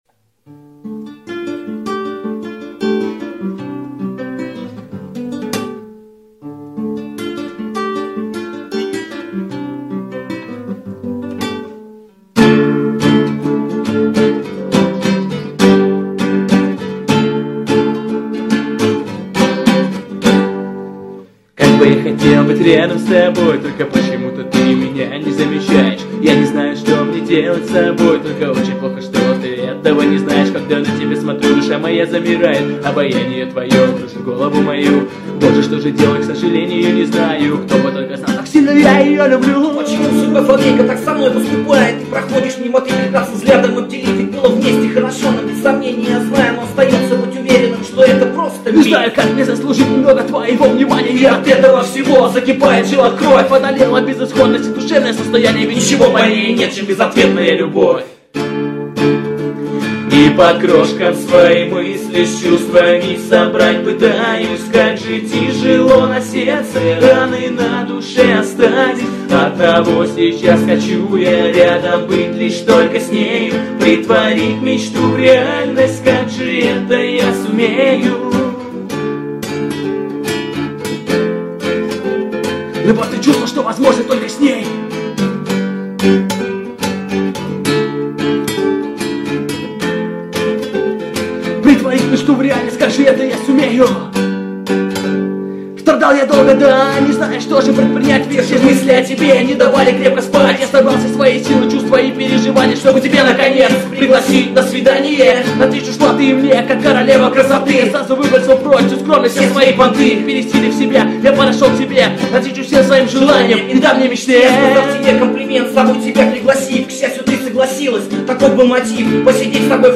РеП / Гитара / Дворовые